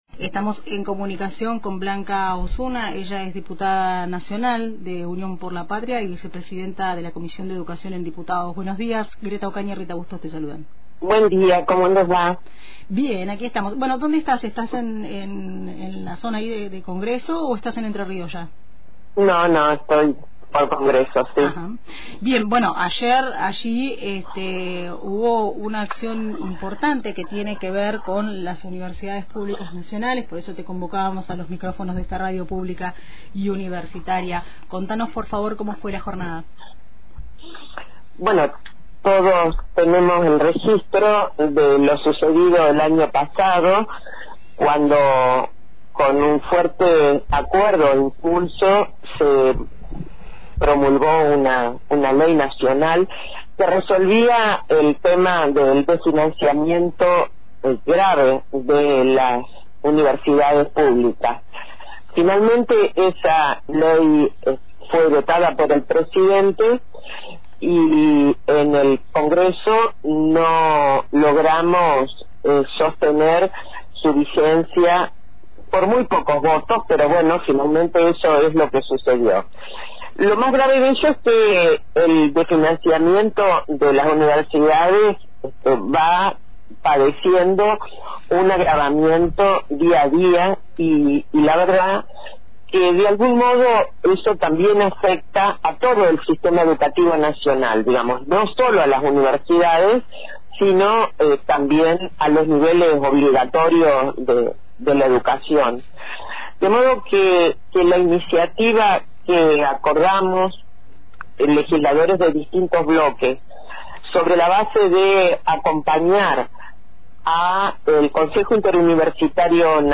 En comunicación con Blanca Osuna, Diputada nacional de Unión por la Patria y vicepresidenta de la Comisión de Educación en Diputados, dialogamos sobre el nuevo proyecto de presupuesto universitario impulsado por los bloques opositores.